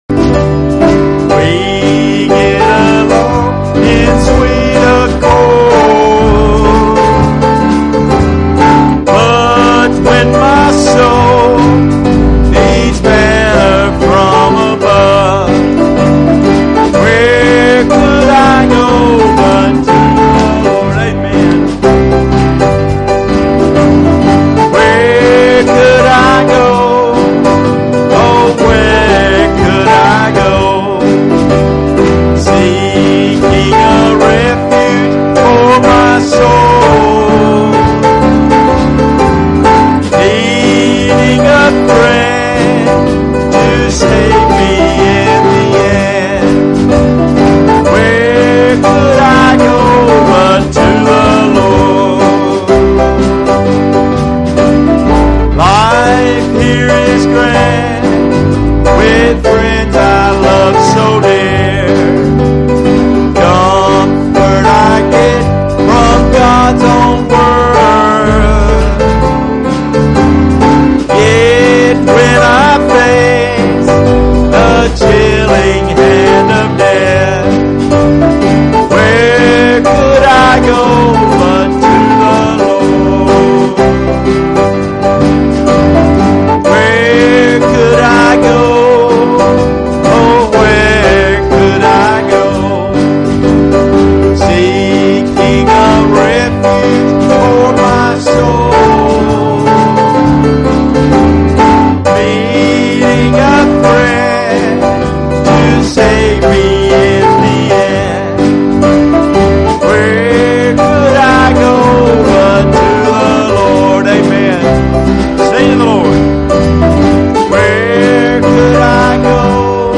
Passage: Ezekiel 37:1-5 Service Type: Wednesday Evening Services Topics